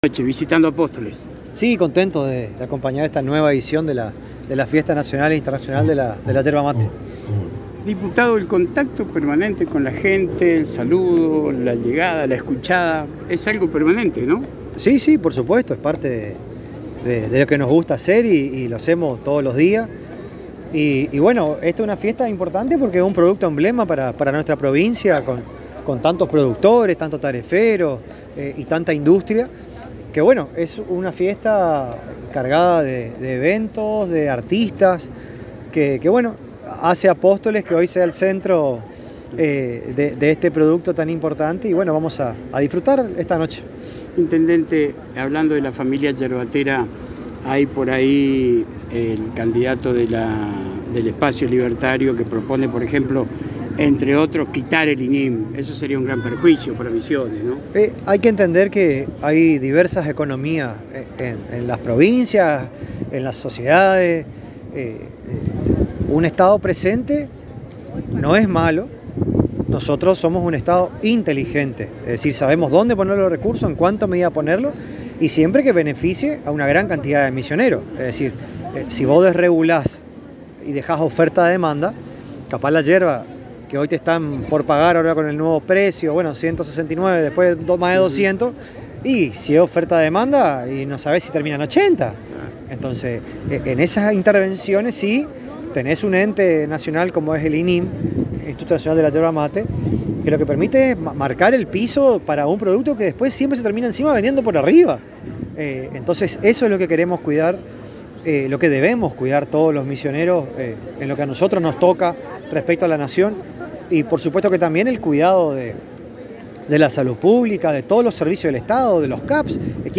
El Diputado Provincial y Vice Gobernador electo C.P.N. Lucas Romero Spinelli visitando la Ciudad de Apóstoles con motivo de la 45 Fiesta Nacional e Internacional de la Yerba Mate, accedió a dialogar con la ANG en forma exclusiva, manifestando su alegría de estar en este momento en Apóstoles participando de esta fiesta que representa el producto madre de la región.